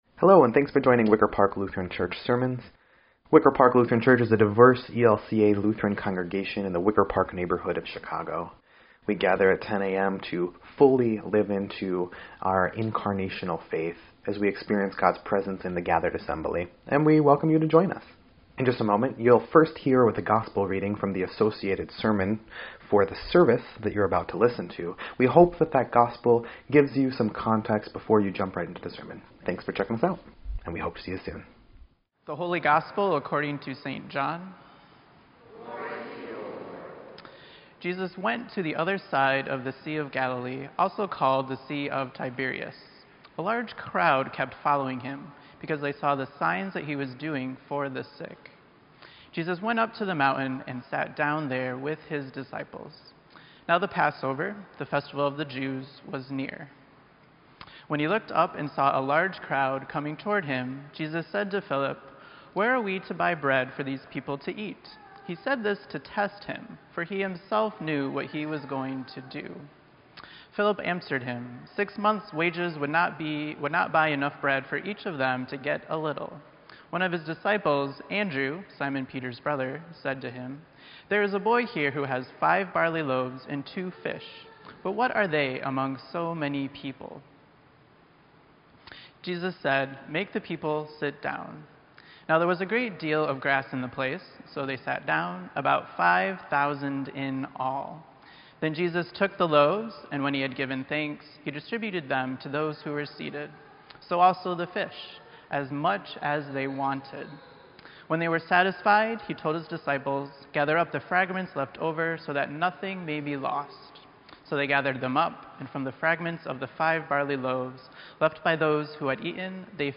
Sermon_7_29_18_EDIT.mp3